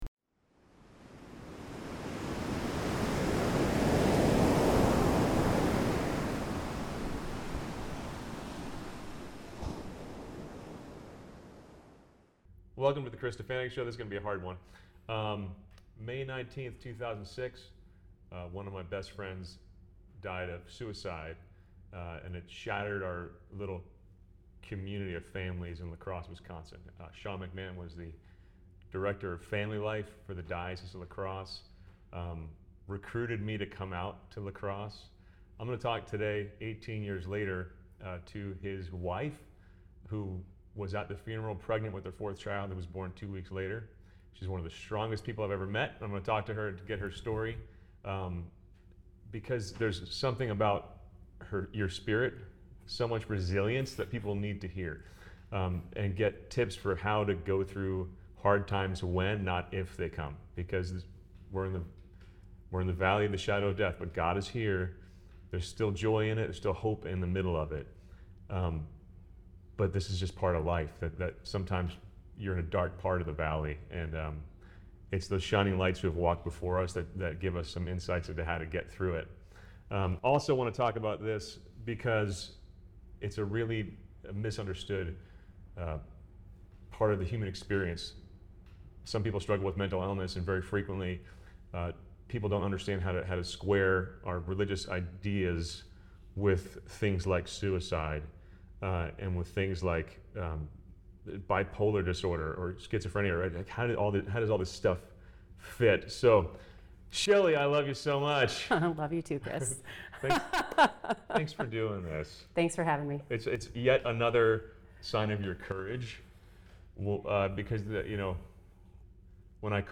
Guys, this is a tough conversation.